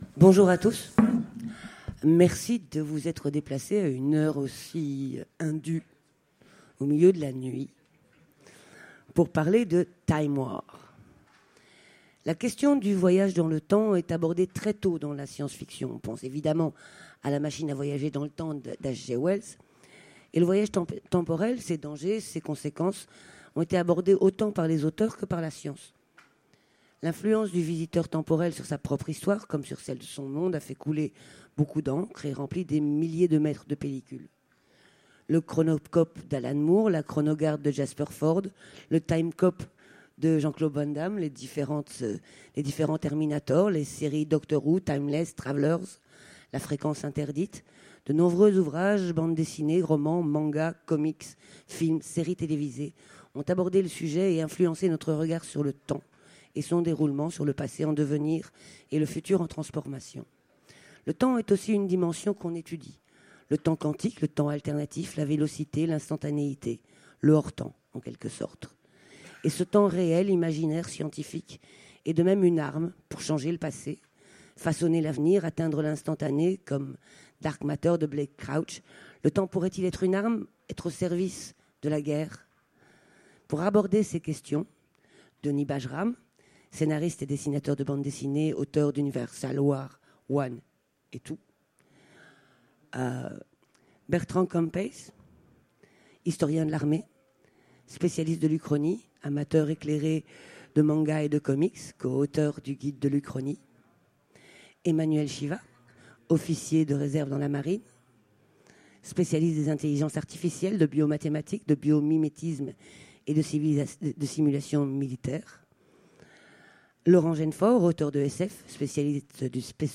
Utopiales 2017 : Conférence Time war